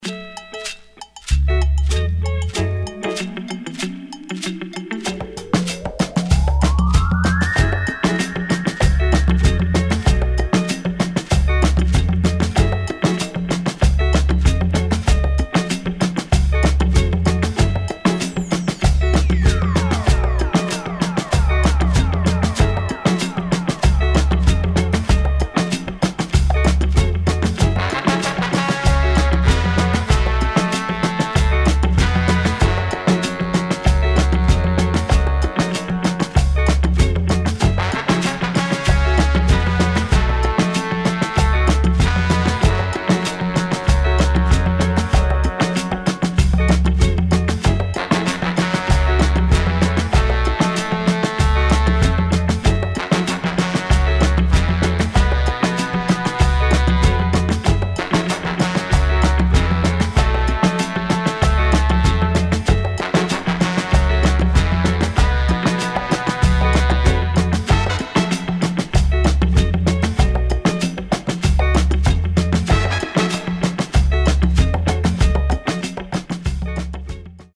[ DOWNBEAT / AFRO / FUNK ]